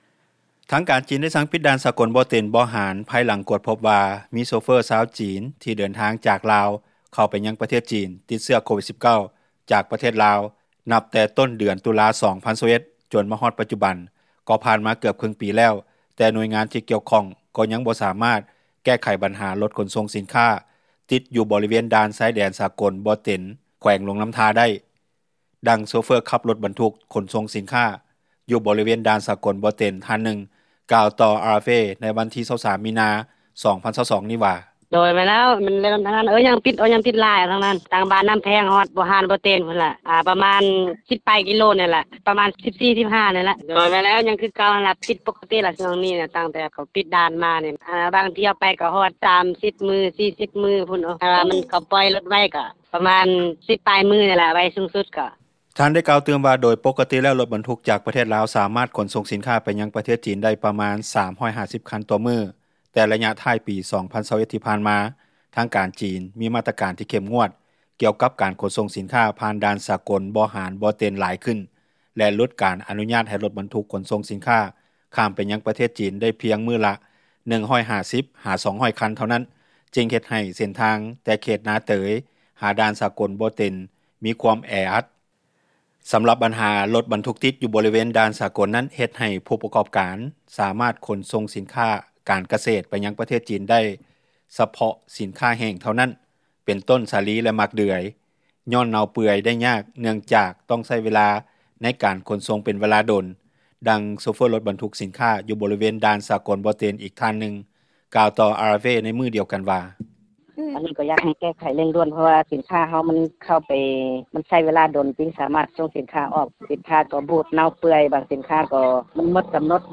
ດັ່ງໂຊເຟີຂັບຣົຖບັນທຸກ ຂົນສົ່ງສິນຄ້າຢູ່ບໍຣິເວນ ດ່ານສາກົລບໍ່ເຕັນ ທ່ານນຶ່ງກ່າວຕໍ່ RFA ເມື່ອວັນທີ 23 ມີນາ 2022 ນີ້ວ່າ:
ດັ່ງໂຊເຟີຣົຖບັນທຸກສິນຄ້າ ຢູ່ບໍຣິເວັນດ່ານສາກົລບໍ່ເຕັນ ອີກທ່ານນຶ່ງກ່າວຕໍ່ RFA ໃນມື້ດຽວກັນນີ້ວ່າ: